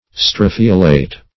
Search Result for " strophiolate" : The Collaborative International Dictionary of English v.0.48: Strophiolate \Stro"phi*o*late\, Strophiolated \Stro"phi*o*la`ted\, a. (Bot.)